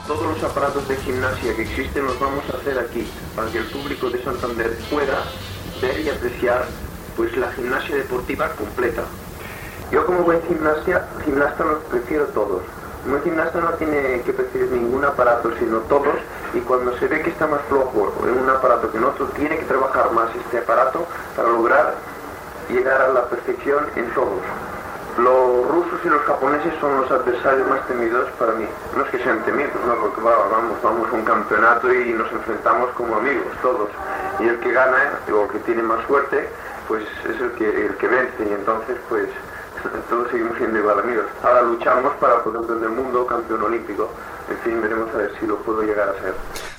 A Santander, el gimnasta català Joaquín Blume parla dels diversos aparells i de la competició gimnàstica
Esportiu
Fragment extret del programa "Audios para recordar" de Radio 5 emès el 28 d'abril del 2014.